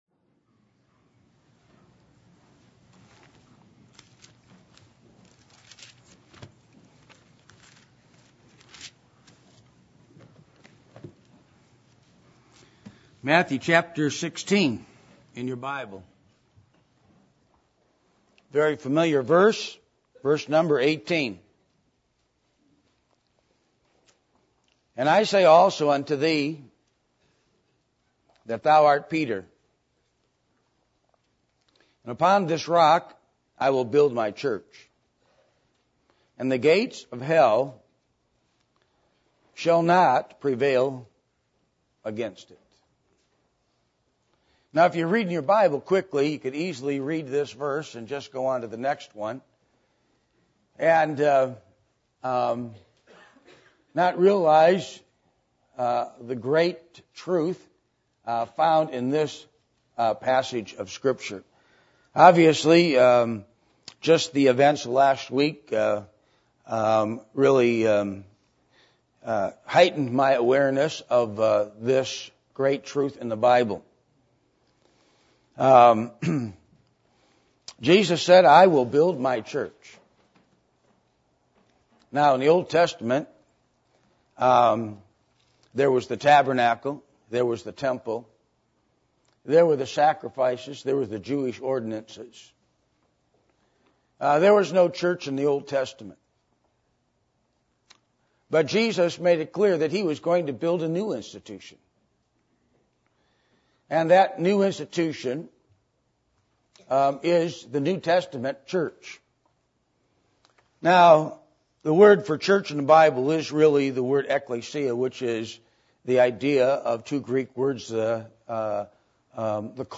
Matthew 16:18 Service Type: Sunday Evening %todo_render% « Proverbs For The Day Of Persecution Expository of Ephesians Chapter 1